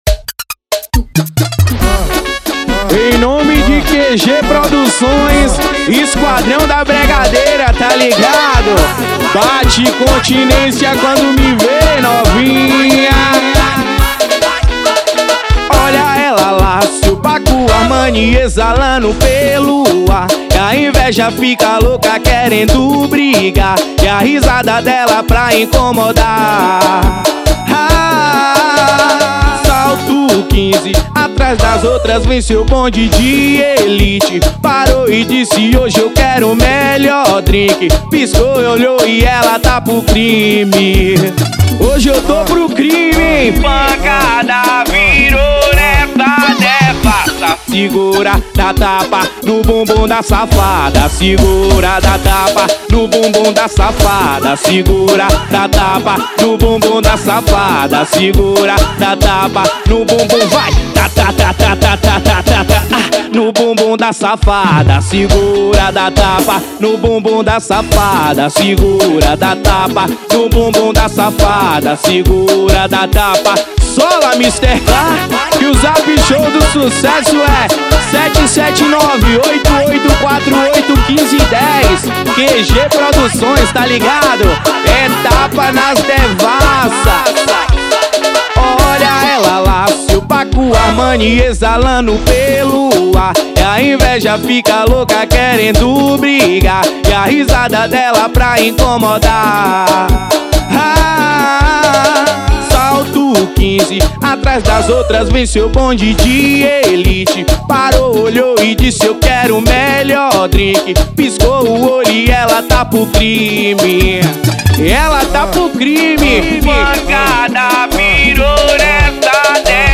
funk.